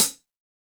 Roland.Juno.D _ Limited Edition _ Brush Kit _ Hh2.wav